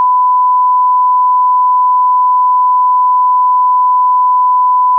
tone_44100_stereo.wav